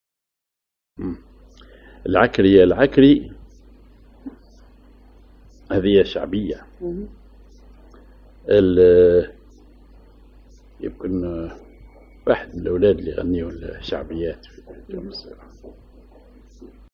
Maqam ar محير سيكاه